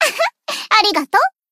贡献 ） 分类:蔚蓝档案语音 协议:Copyright 您不可以覆盖此文件。
BA_V_Mutsuki_Battle_Buffed_1.ogg